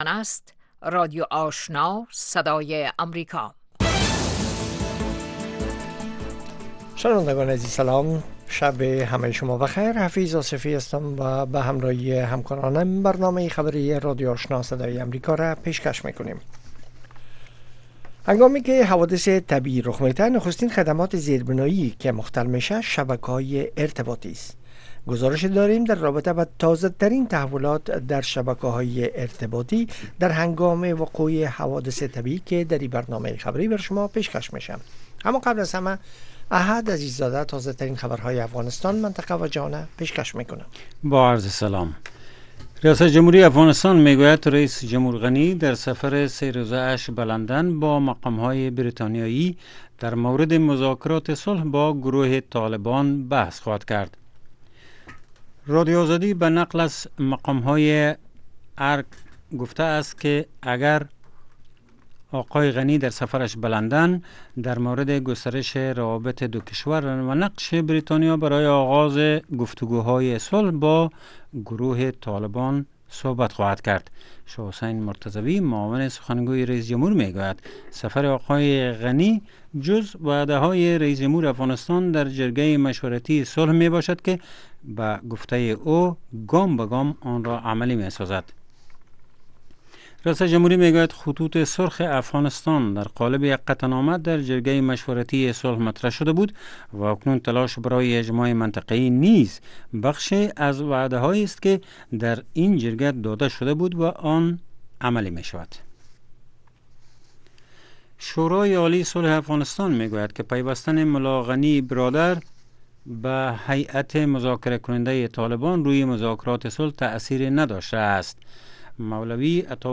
در برنامه خبری شامگاهی، خبرهای تازه و گزارش های دقیق از سرتاسر افغانستان، منطقه و جهان فقط در سی دقیقه پیشکش می شود.